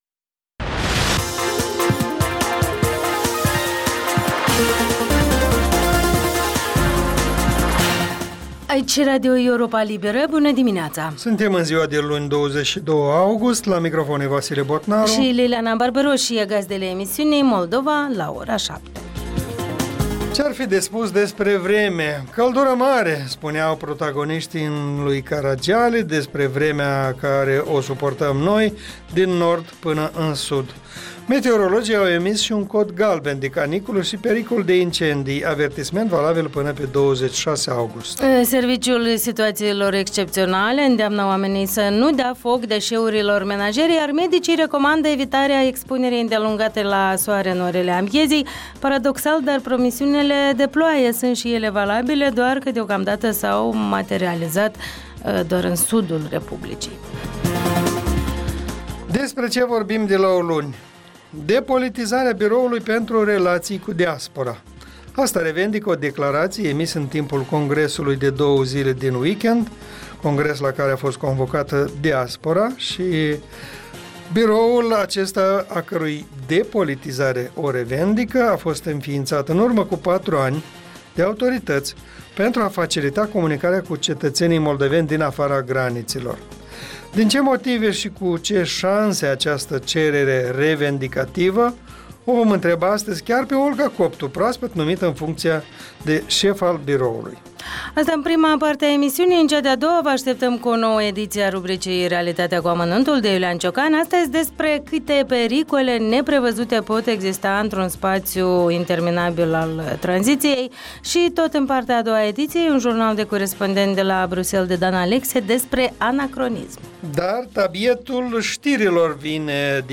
Ştiri, interviuri, analize.